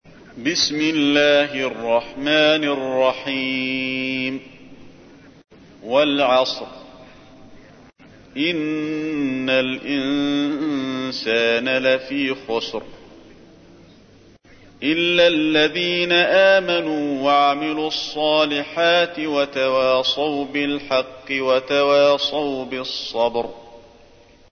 تحميل : 103. سورة العصر / القارئ علي الحذيفي / القرآن الكريم / موقع يا حسين